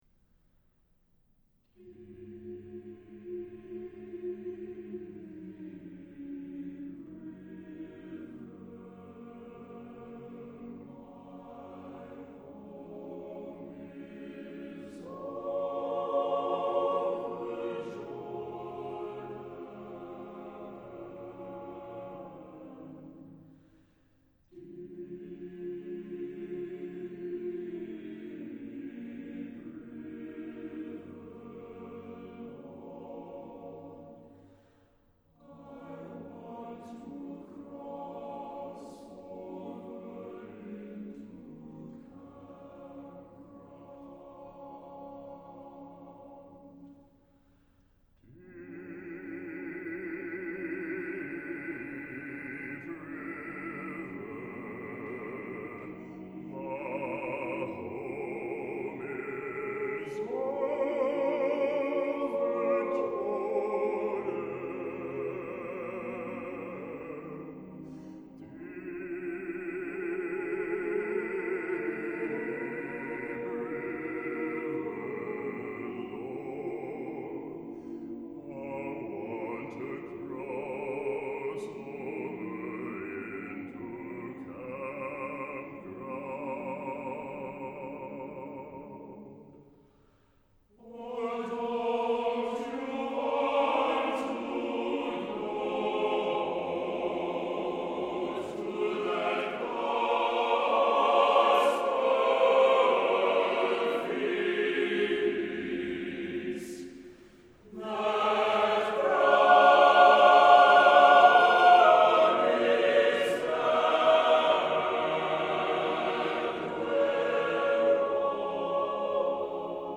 Music Category:      Choral
spiritual